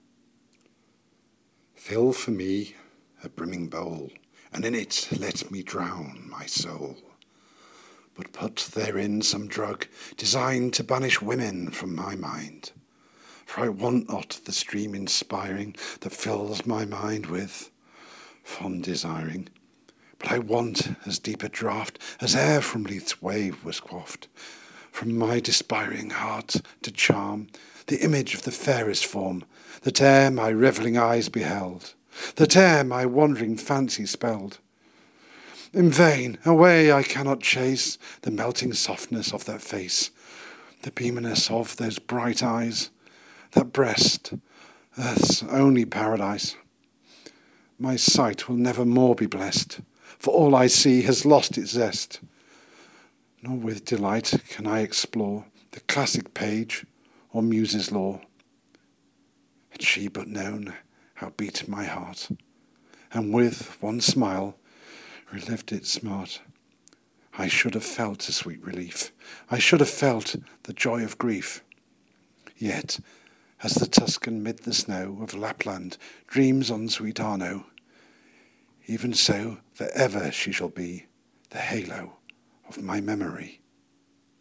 I have recorded 'Fill for me a brimming bowl' by Keats.